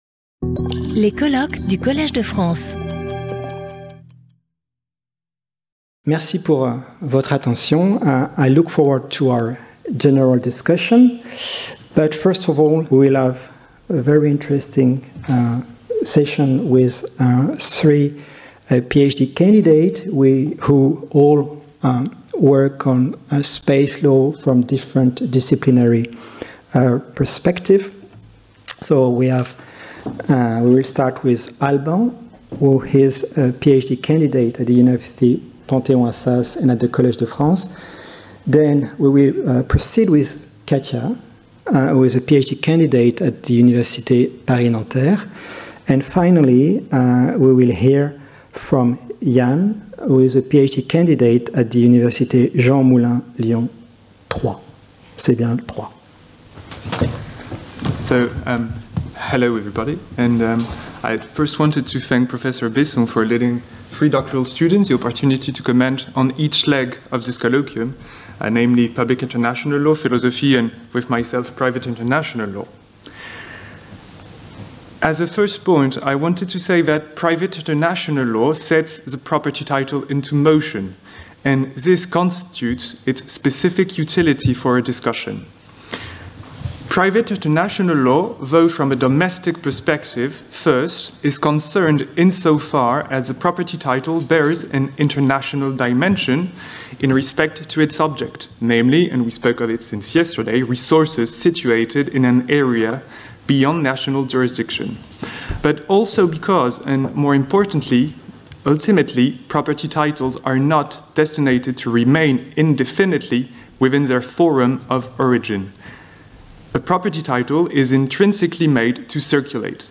General Discussion, introduced and chaired by Young Researchers | Collège de France